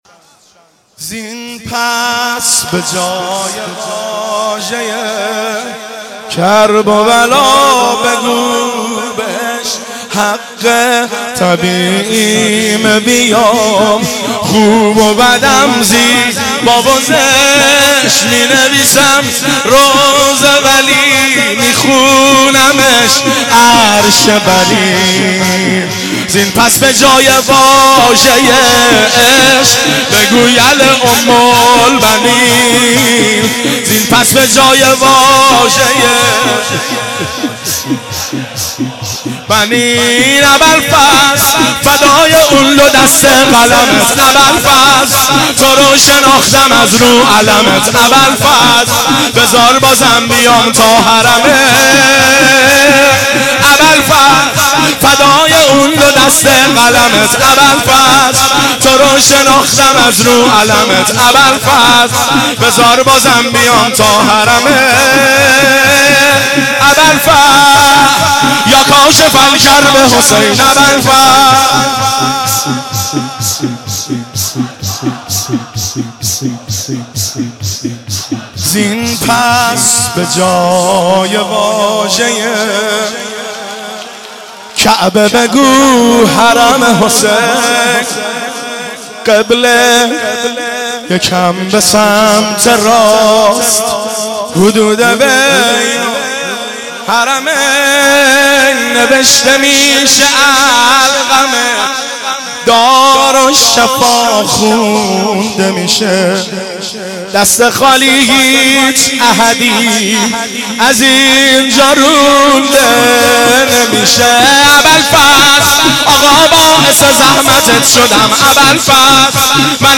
صوت مراسم شب نهم محرم (تاسوعا) ۱۴۳۷ هیئت غریب مدینه امیرکلا ذیلاً می‌آید: